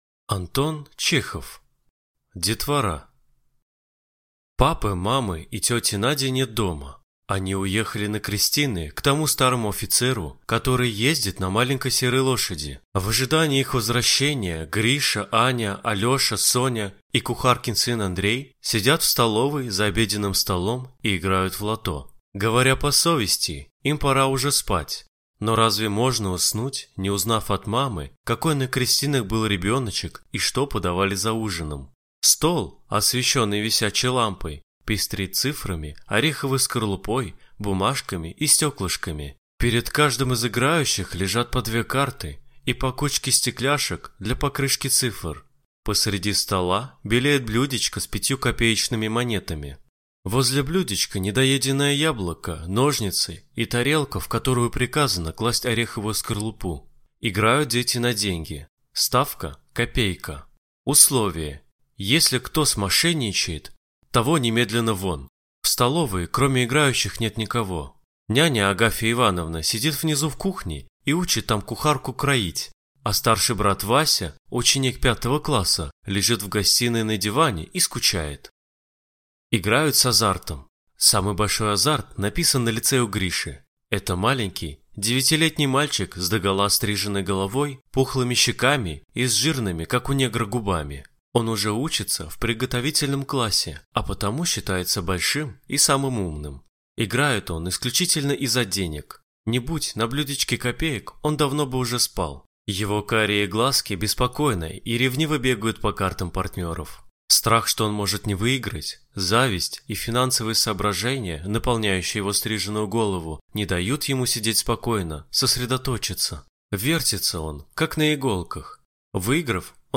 Аудиокнига Детвора | Библиотека аудиокниг